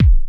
Kick_103.wav